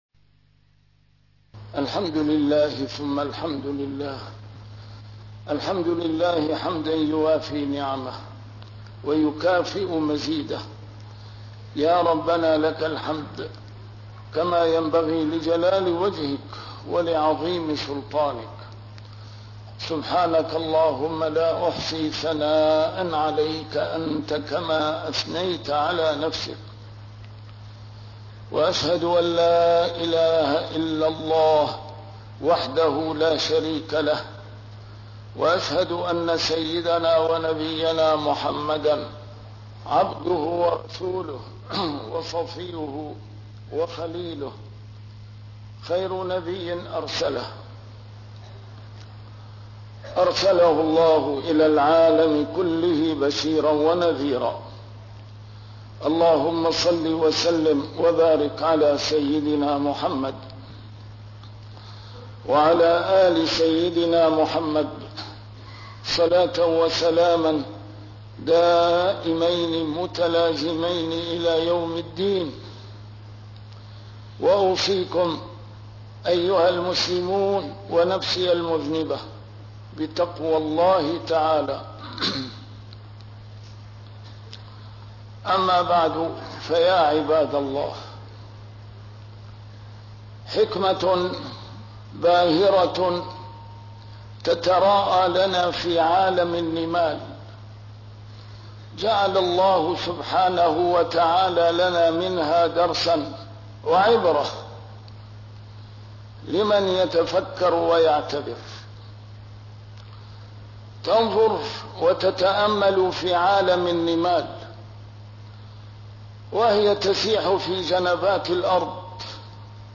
A MARTYR SCHOLAR: IMAM MUHAMMAD SAEED RAMADAN AL-BOUTI - الخطب - وحدة الصف أولى المقدمات لحراسة دين الله